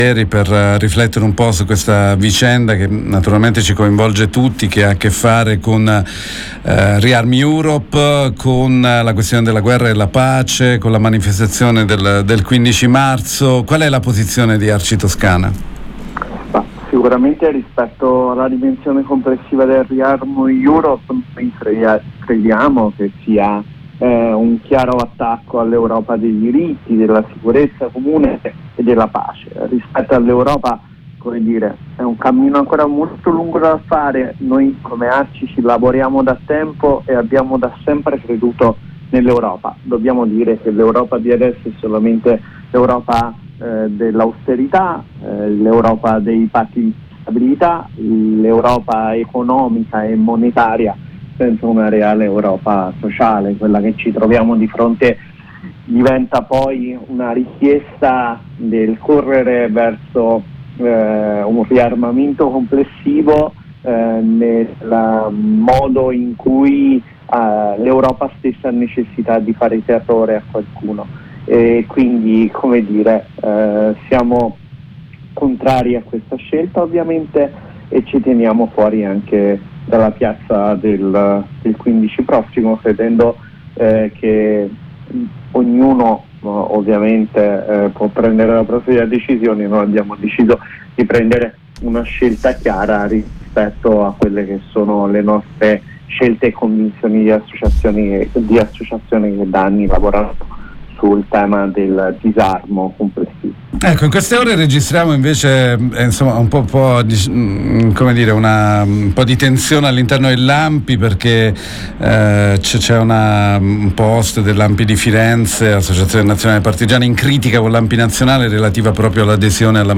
paceIntervista